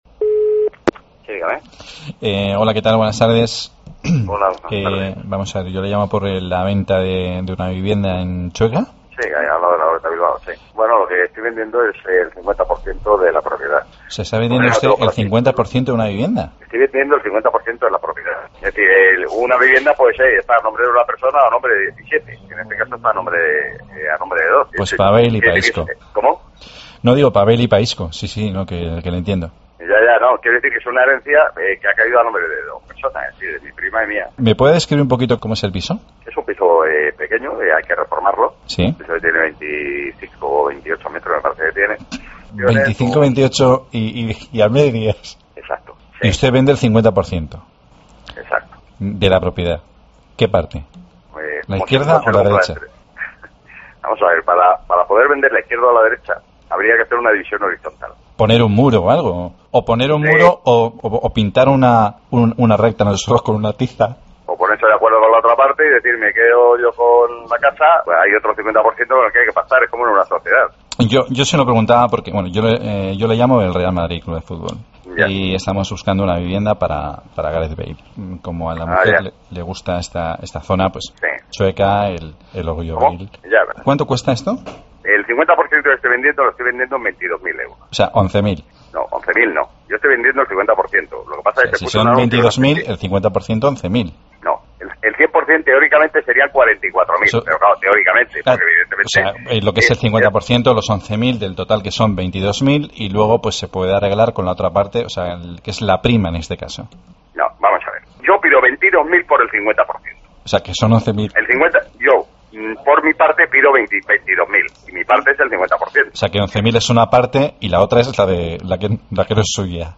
Llamada de Butragueño comprando un pisazo para Bale